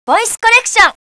Voice collection 22KB Ohayougozaimasu 27KB sayonara 15KB system error desu 30KB sampling rate: 22.05kHz, 8Bits, monaural.